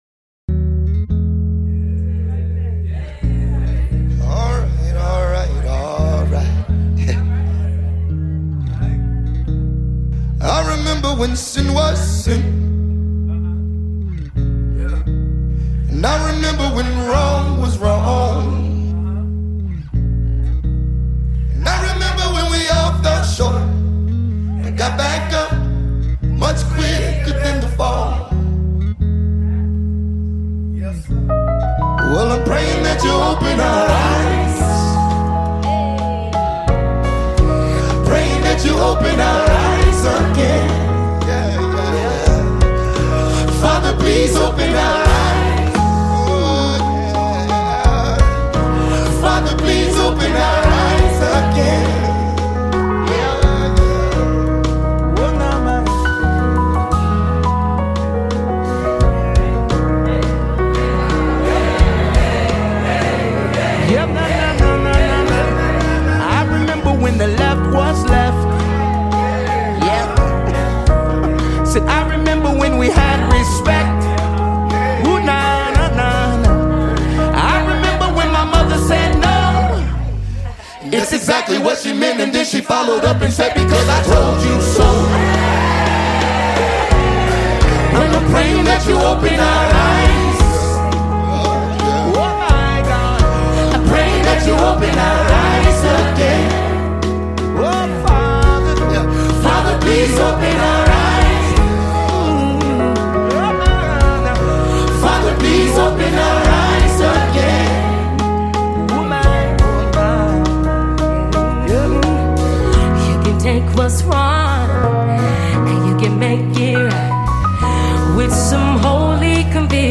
American Gospel Group
live performance